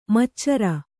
♪ maccara